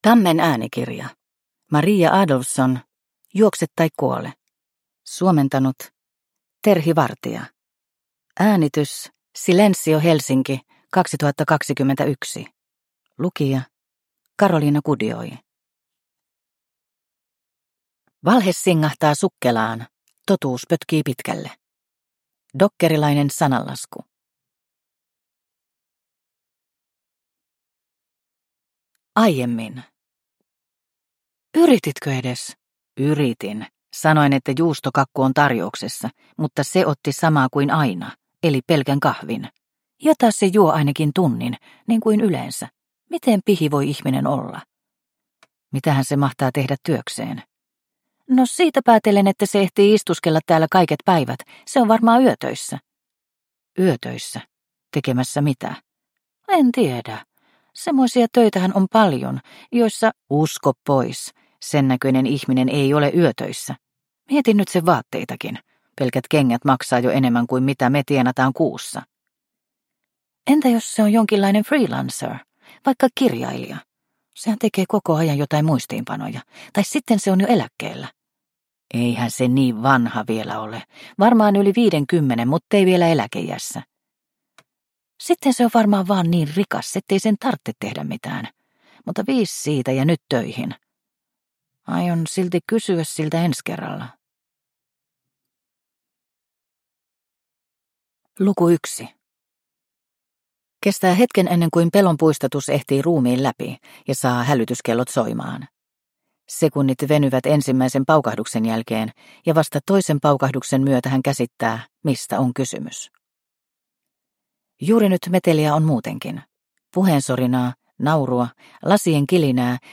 Juokse tai kuole – Ljudbok – Laddas ner